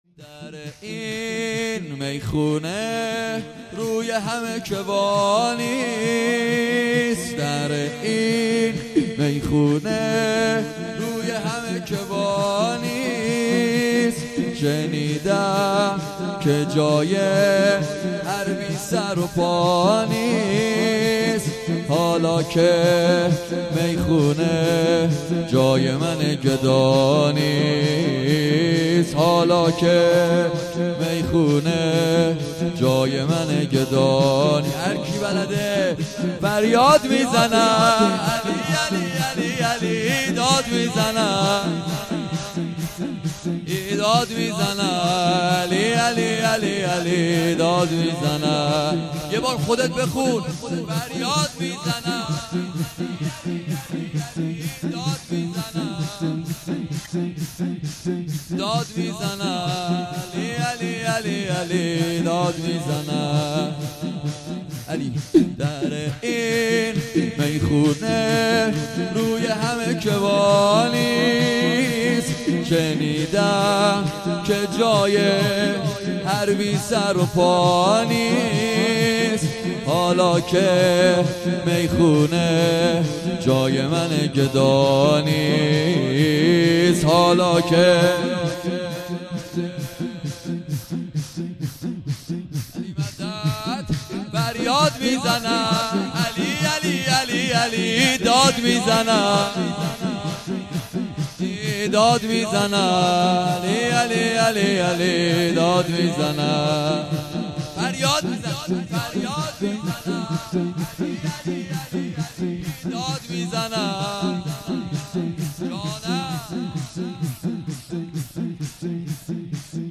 سرود زیبا